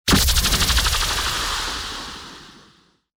debrislaser.wav